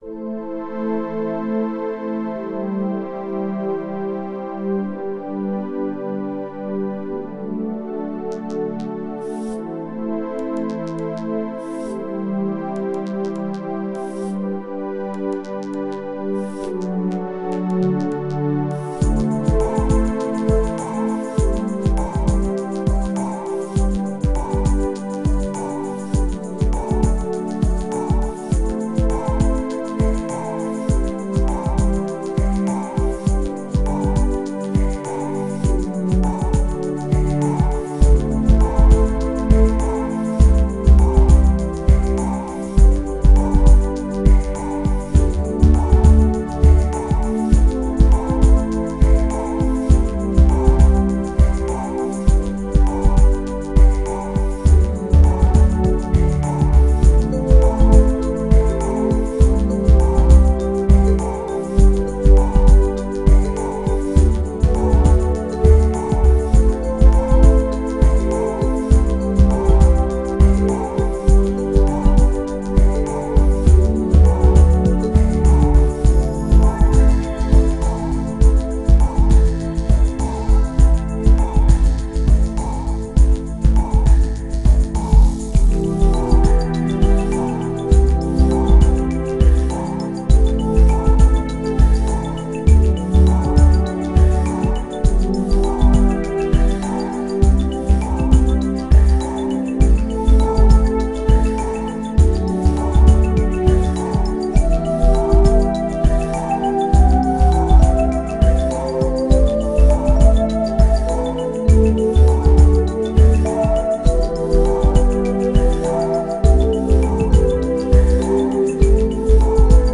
BPM101
Audio QualityPerfect (High Quality)
Comments(FULL SONG)